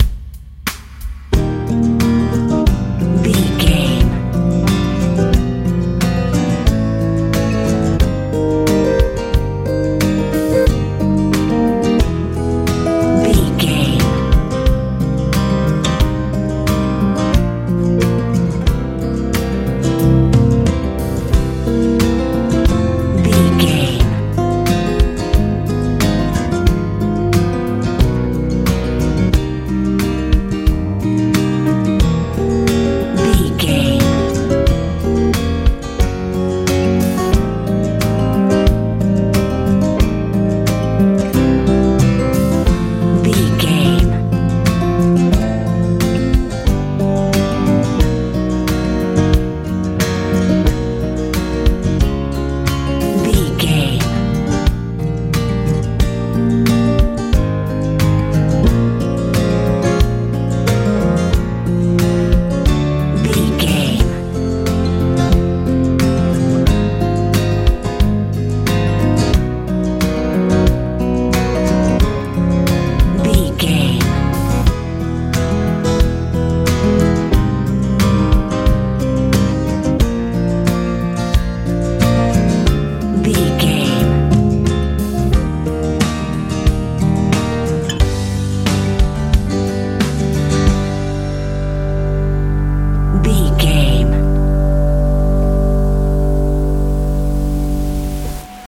easy listening
Ionian/Major
E♭
light
acoustic guitar
piano
drums
bass guitar
soft
smooth
relaxed